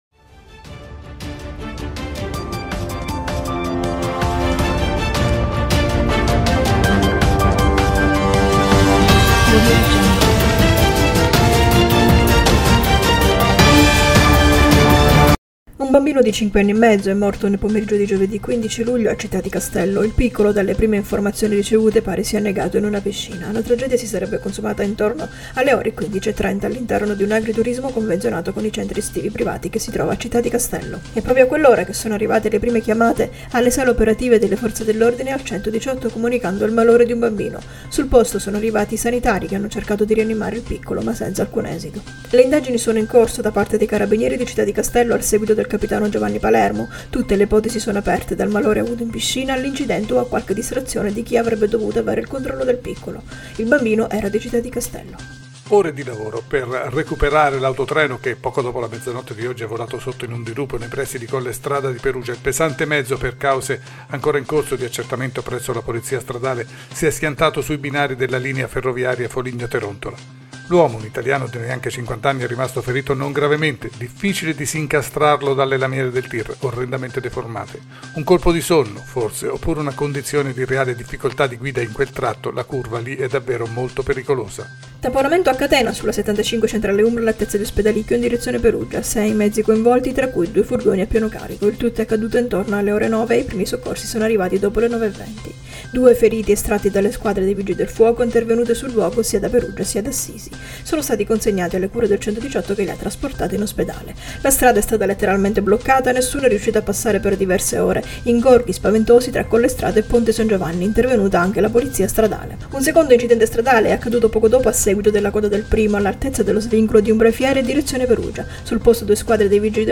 Tg dell’Umbria, Radiogiornale della sera, ultime notizie 15.07.2021